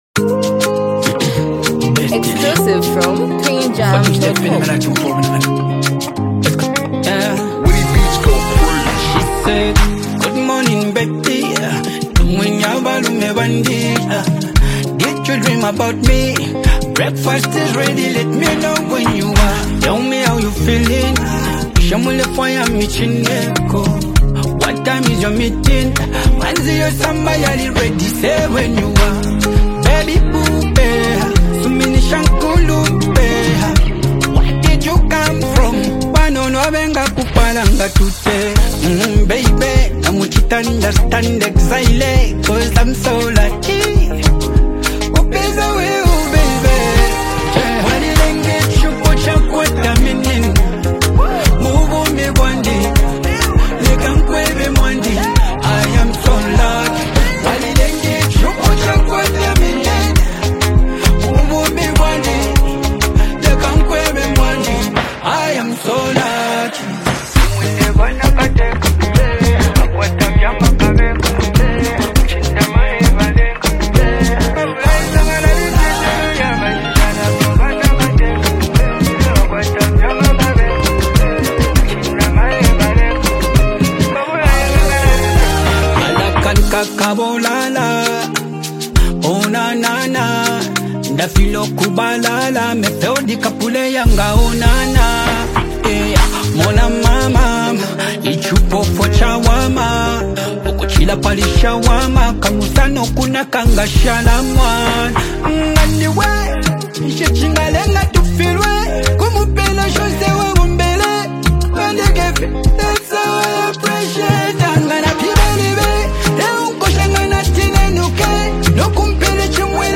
feel-good love song
soft instrumental
creating an atmosphere of warmth and intimacy.